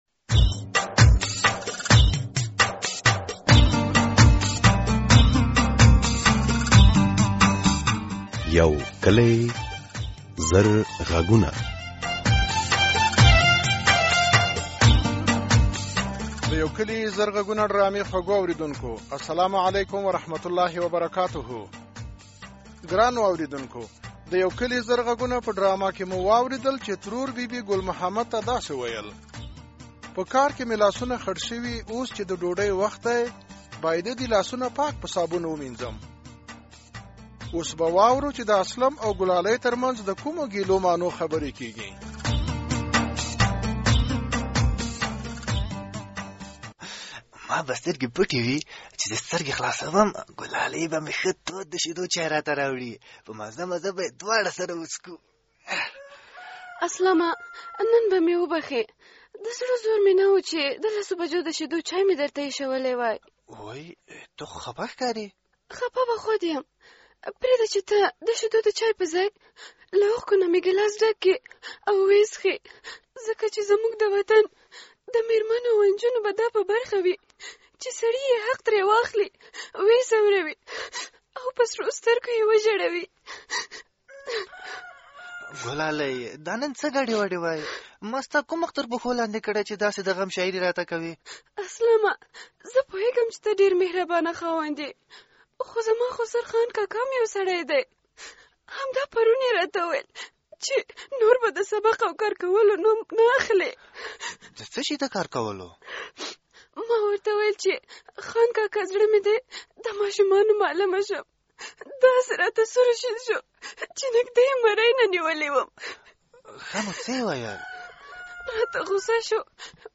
د یو کلي زرغږونو په ډرامه کې اورئ چې موسی ګل په لاره کې د غلو له شکونو وېرېږي او دباندې په یوه امن ځای کې شپه ...